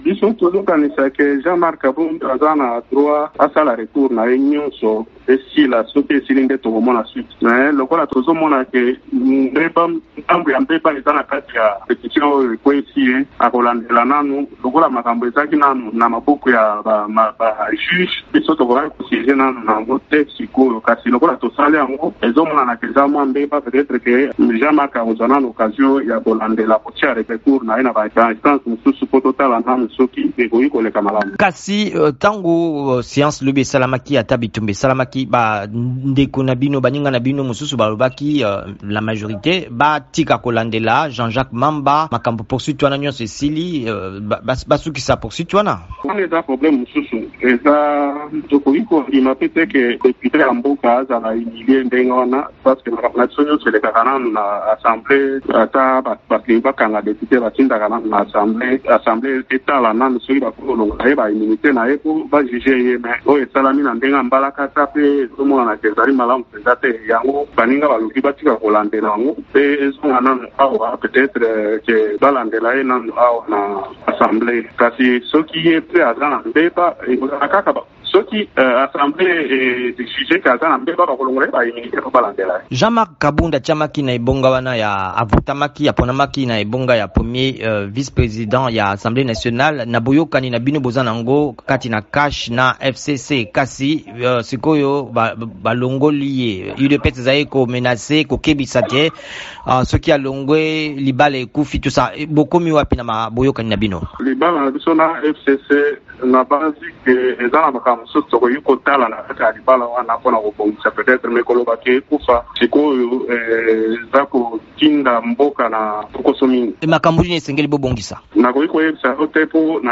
Kasi elobi libala CACH-FCC ekufi te. VOA Lingala ebangaki mokambi ya groupe parlementaire UDPS Léon Mubikayi.